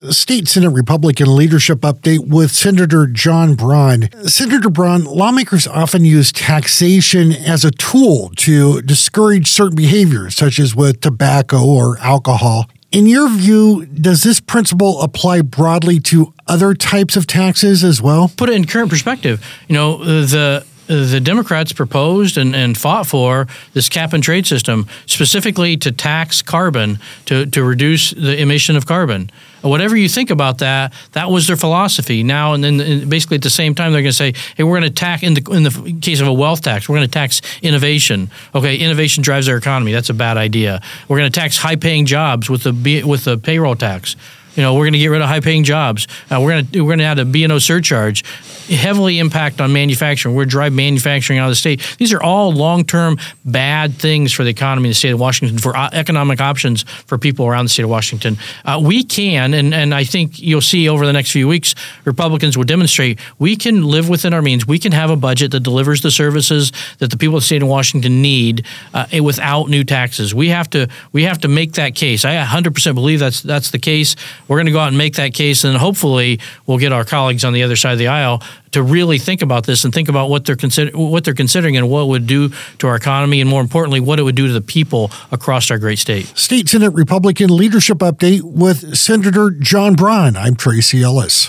AUDIO: State Senate Republican leadership update with Senator John Braun - Senate Republican Caucus